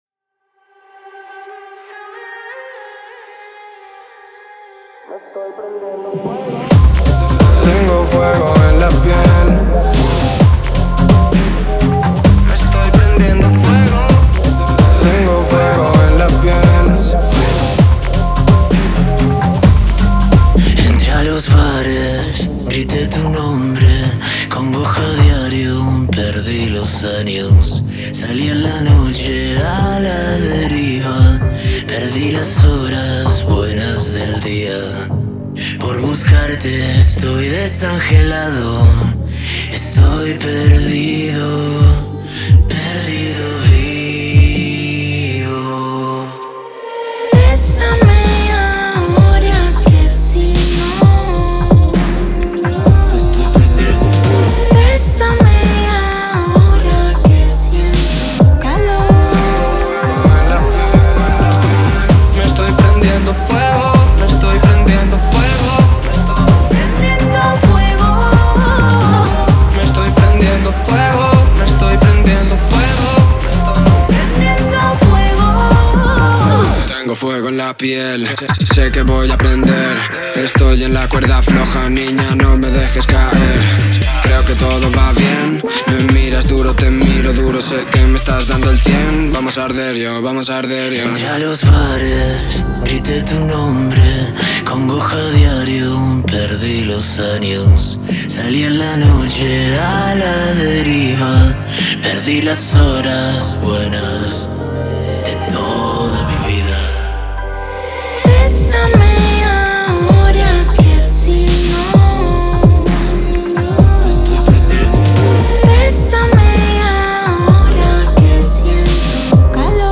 это захватывающая композиция в жанре фламенко